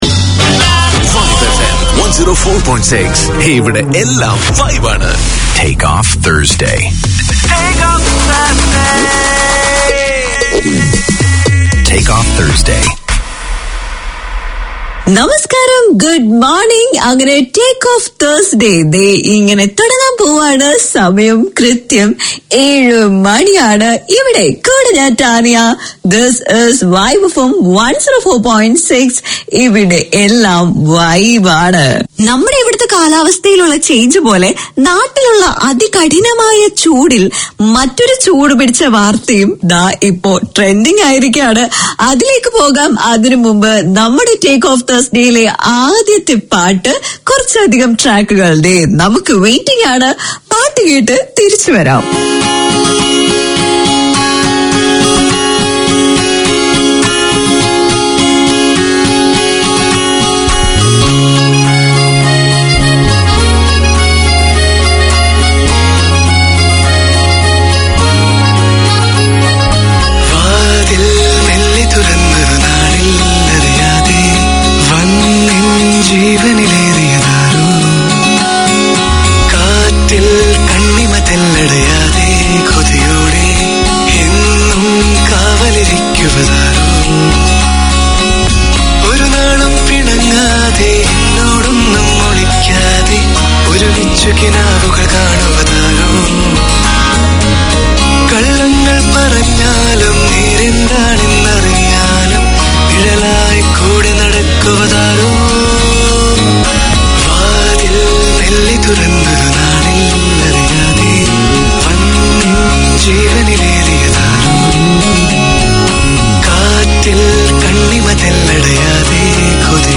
Community Access Radio in your language - available for download five minutes after broadcast.
A celebration of Malayalam language, literature, music and culture; Malayalam FM presents three weekly programmes. Hear dramas and stories based on Malayalam songs on Fridays, film and music on Saturdays, and enjoy a talk-based show featuring discussions and interviews on the Sunday edition.